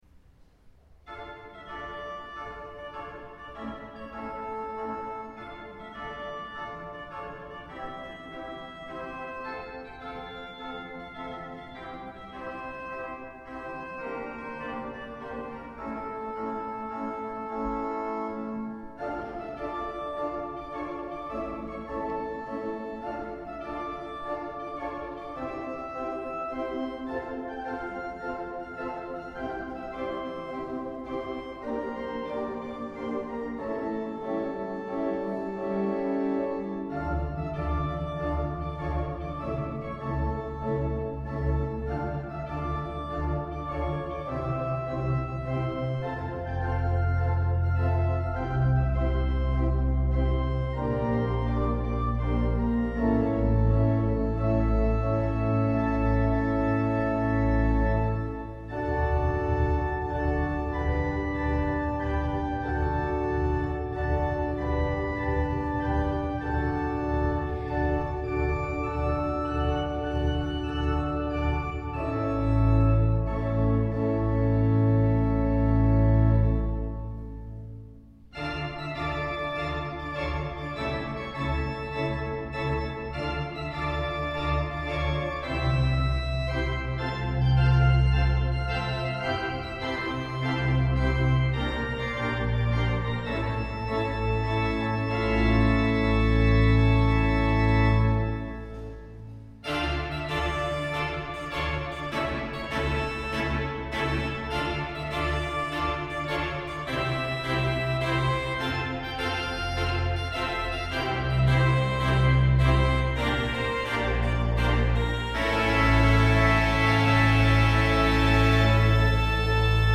Concierto celebrado en Collbató del 50º aniversario del Órgano del Sol Mayor de Marbella.
Arreglo para órgano